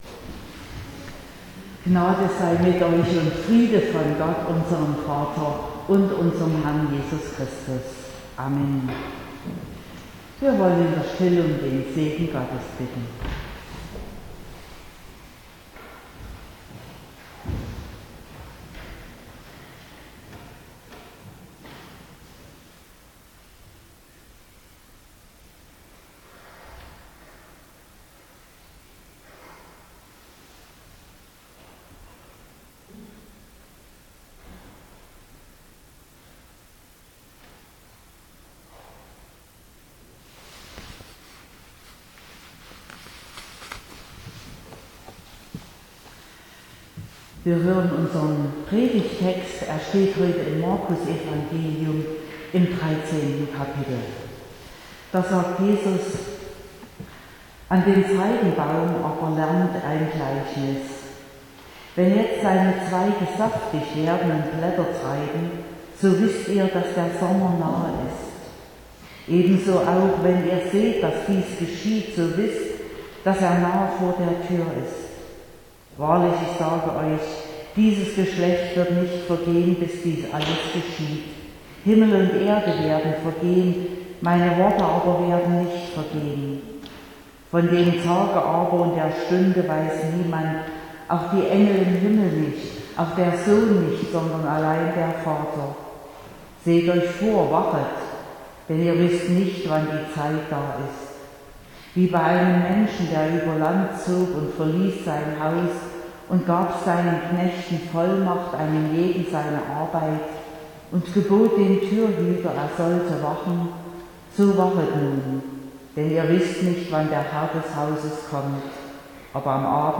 20.11.2022 – Gottesdienst
Predigt und Aufzeichnungen